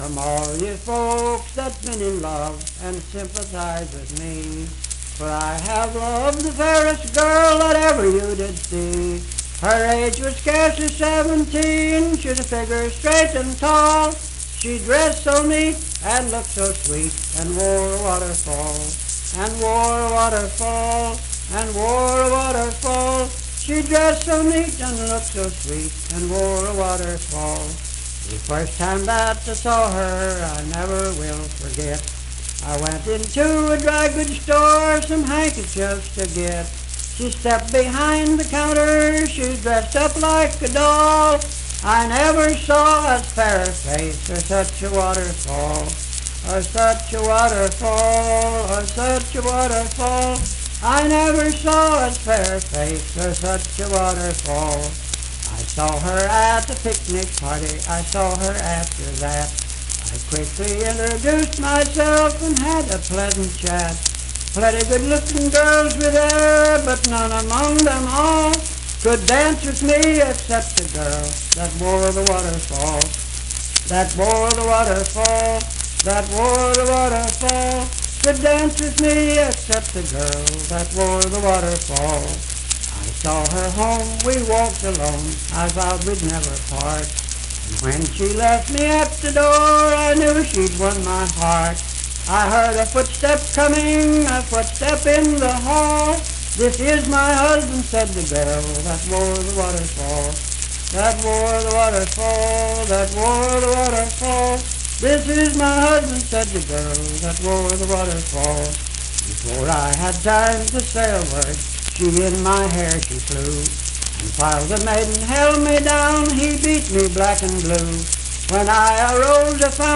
Unaccompanied vocal music
Verse-refrain 5d(6w/R).
Performed in Oak Point, WV.
Voice (sung)